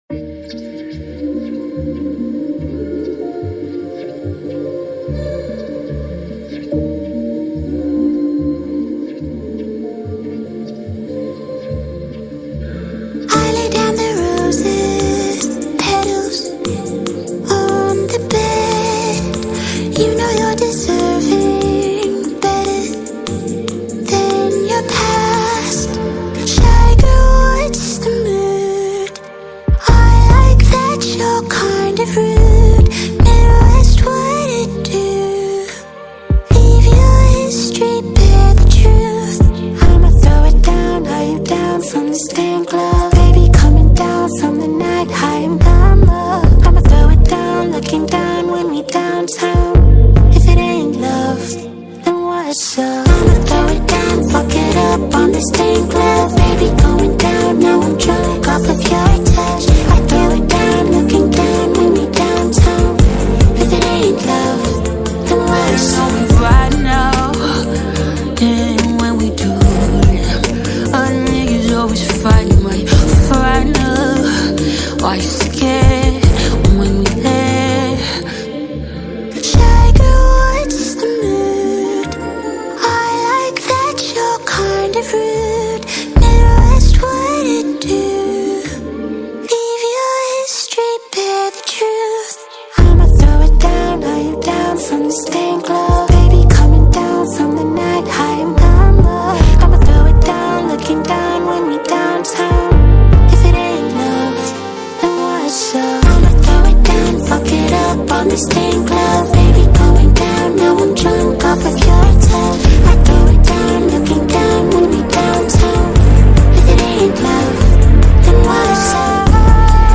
آلترناتیو آراندبی و سول مدرن